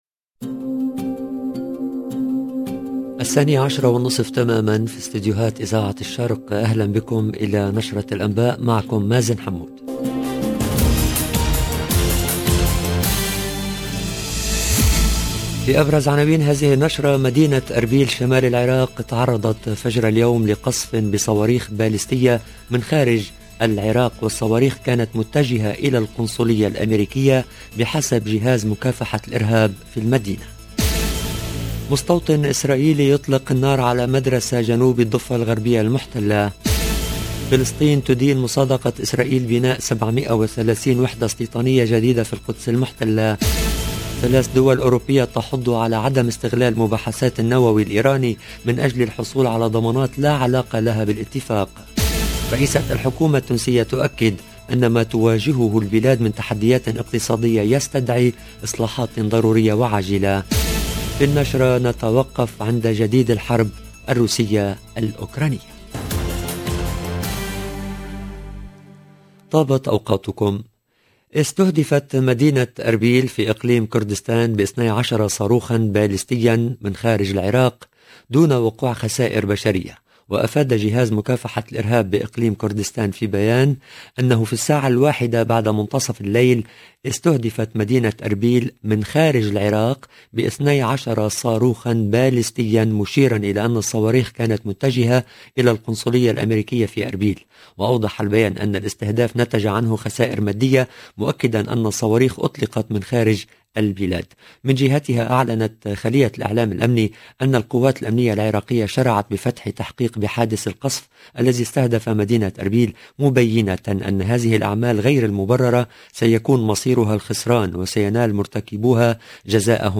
LE JOURNAL DE 12H30 EN LANGUE ARABE DU 13/3/2022